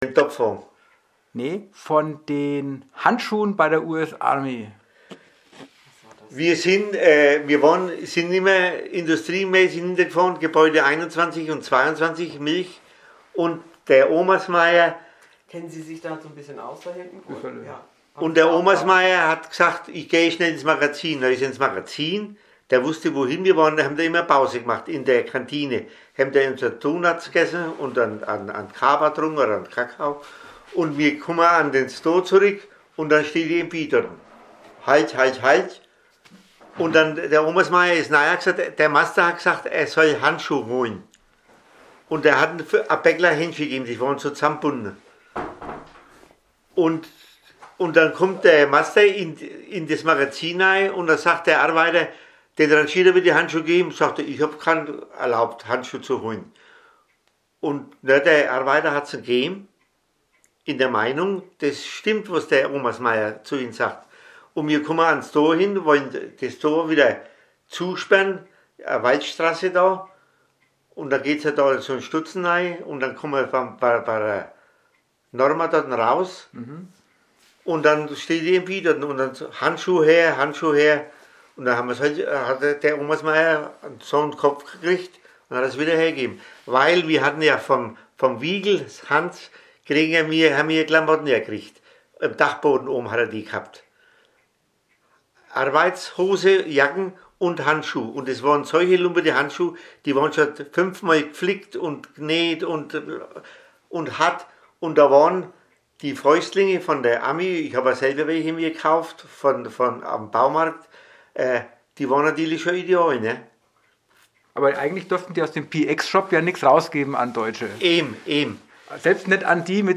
Zeitzeugenberichte